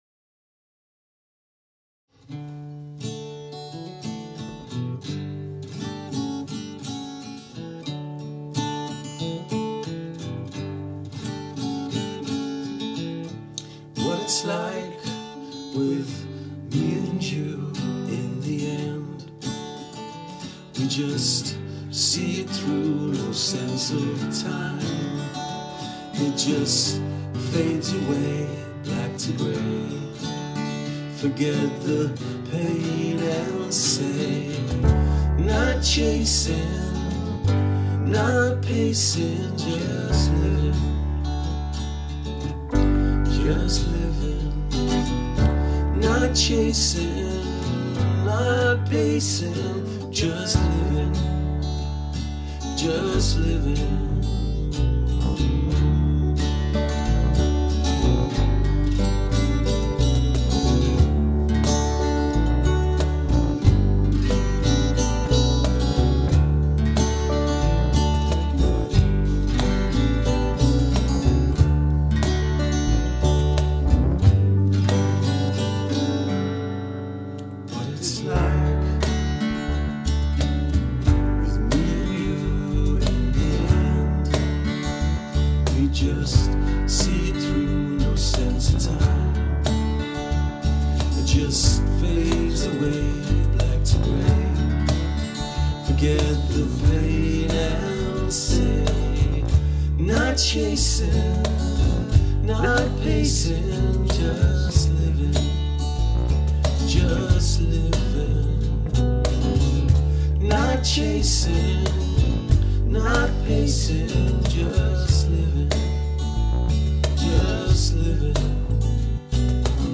keyboard, bass, percussion